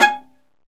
Index of /90_sSampleCDs/Roland L-CD702/VOL-1/STR_Viola Solo/STR_Vla1 % marc